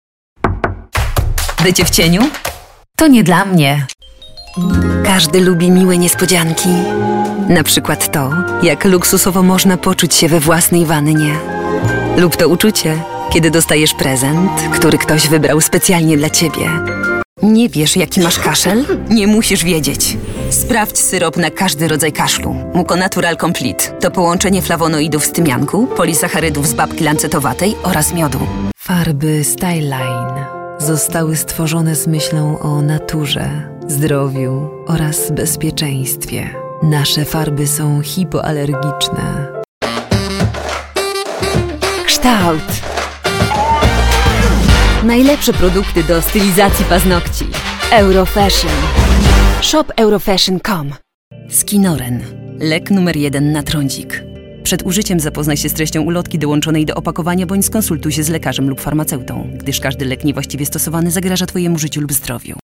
Female 20-30 lat
Warm and low female voice with a wide range of interpretive possibilities.
Nagranie lektorskie
Spot reklamowy